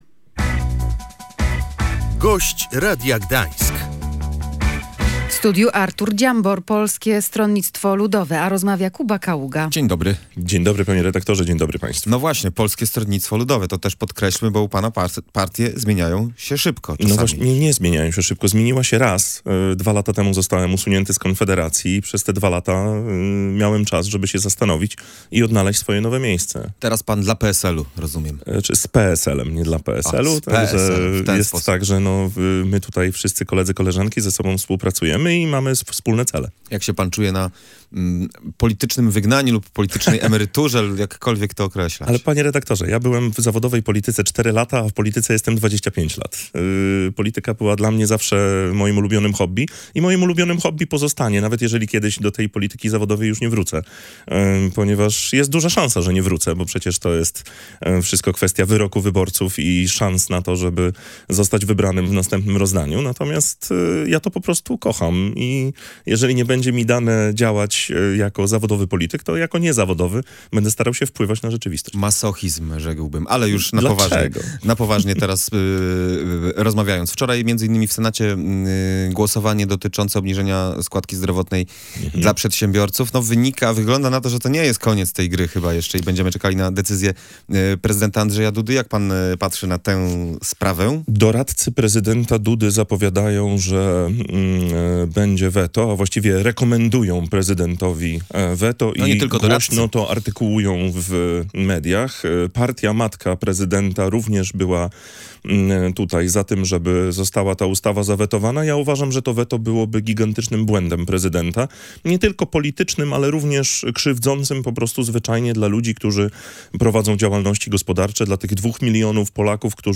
Prezydenckie weto dla ustawy obniżającej składkę zdrowotną dla przedsiębiorców byłoby krzywdzące dla wielu osób pracujących na własny rachunek – uważa Artur Dziambor z Polskiego Stronnictwa Ludowego, czwartkowy „Gość Radia Gdańsk”.